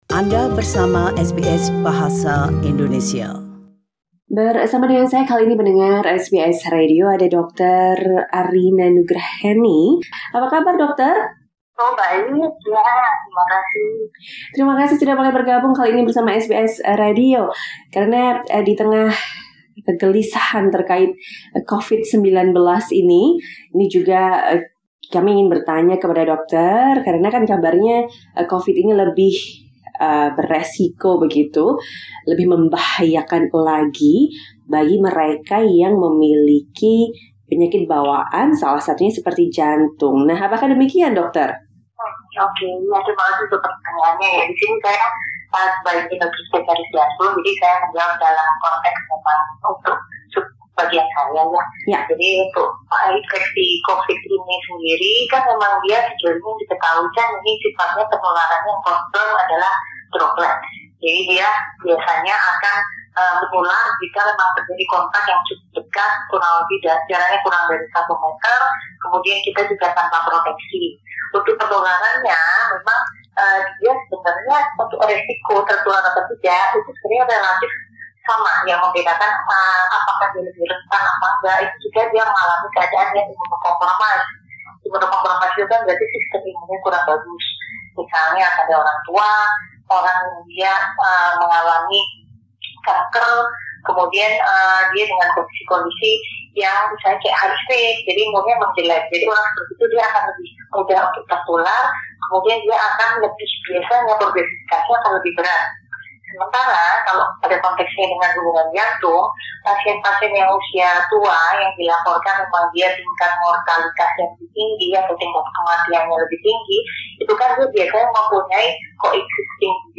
SBS Indonesian asked a cardiologist in Jakarta